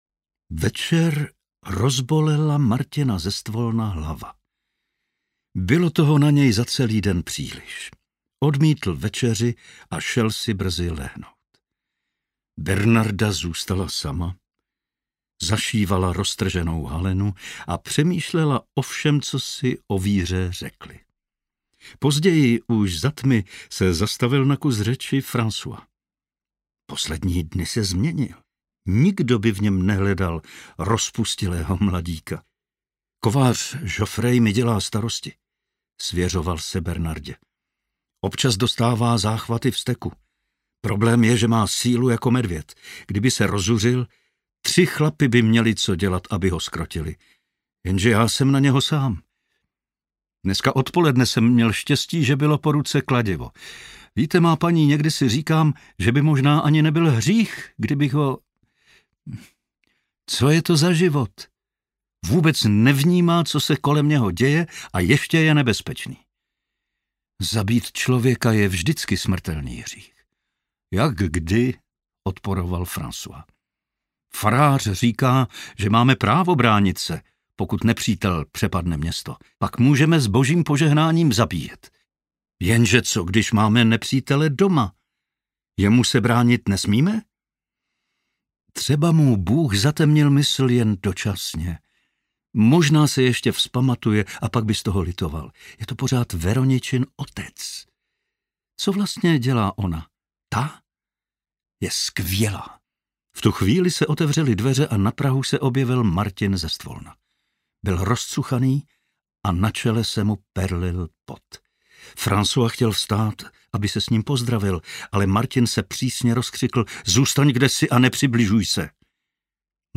Prodavači ostatků II. audiokniha
Ukázka z knihy